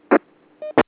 In order to be able to tell which receiver is hearing you and which transmitter you are hearing, some "beeps" have been added.
The different sounds and beeps heard on the 146.620 repeater system.